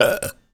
comedy_burp_03.wav